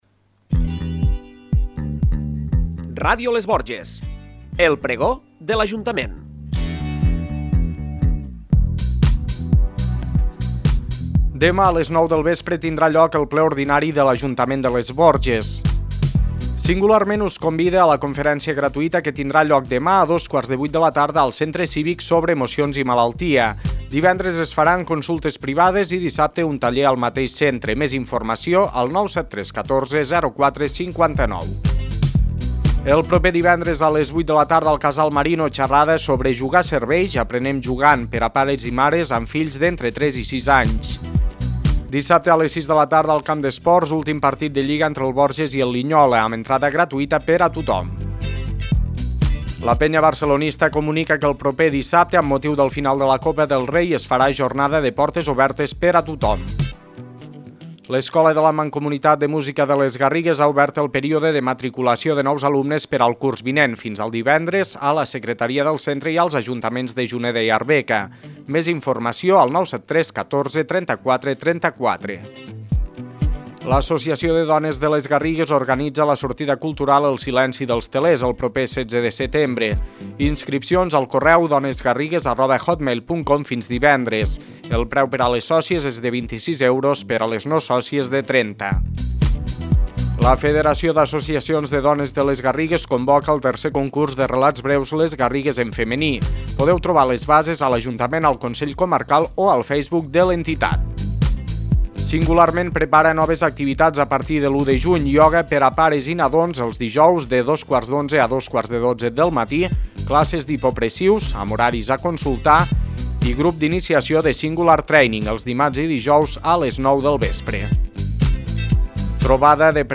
El pregó de l'Ajuntament